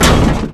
car_heavy_5.wav